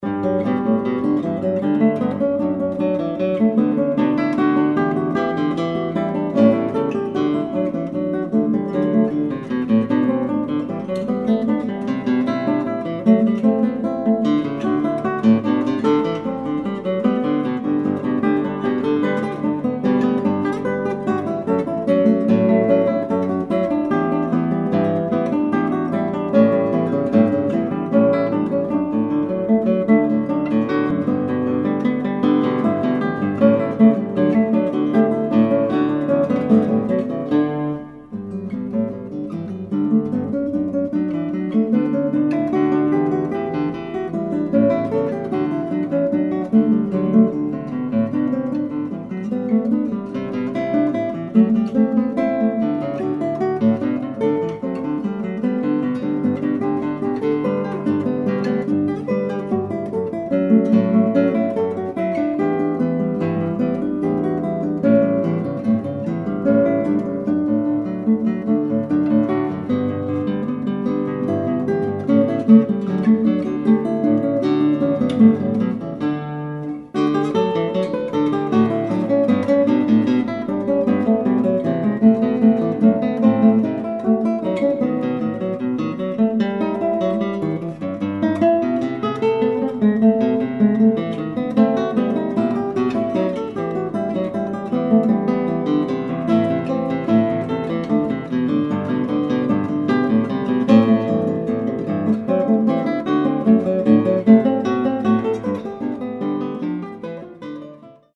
intimacies of color and liquid tone."